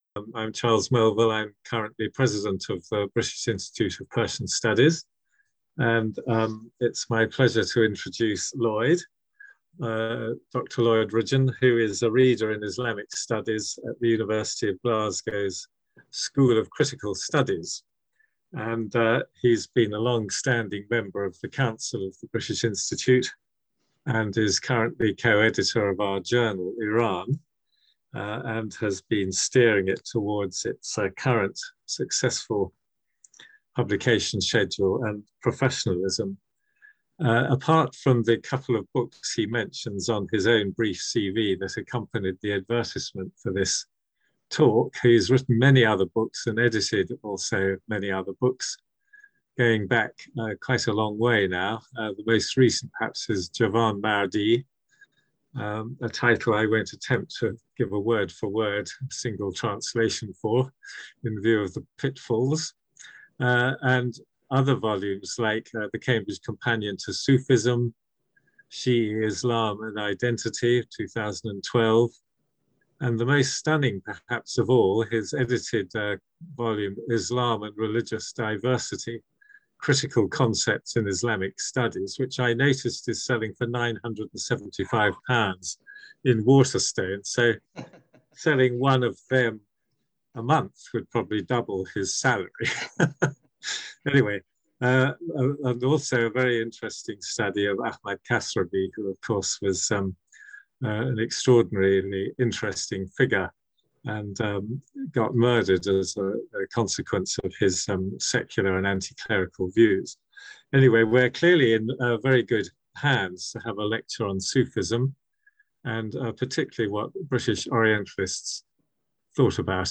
This presentation focuses on these British depictions of Sufism and assesses whether Said’s criticisms were valid. It also outlines the ways in which the legacy of Orientalists studies has influenced Iranian perceptions of Sufism in the twentieth century.